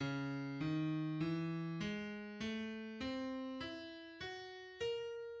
Five Pianos is also well known for a specific motif, a scala enigmatica repeated by every pianist at different tempos specified in each bar containing the motif:[3][4]
\relative c { \clef bass \set Staff.instrumentName = #"Piano" \partial 4*1 cis dis e gis a \clef treble c e fis ais }